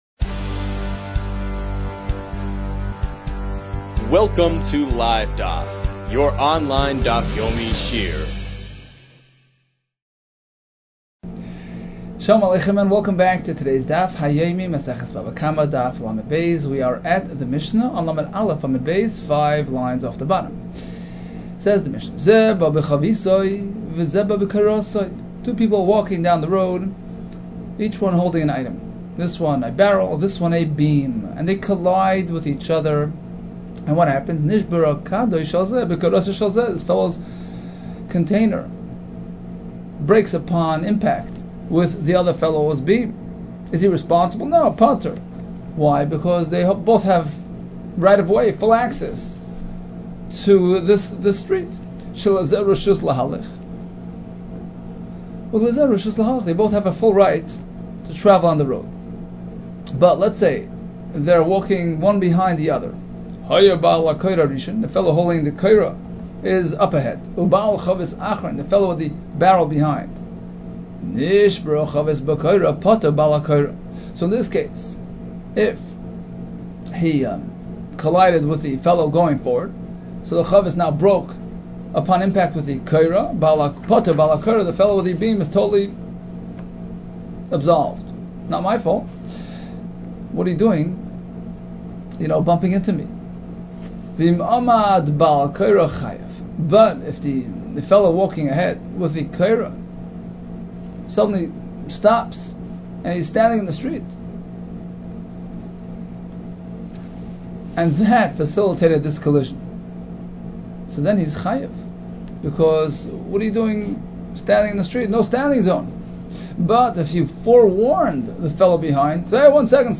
Bava Kamma 32 - בבא קמא לב | Daf Yomi Online Shiur | Livedaf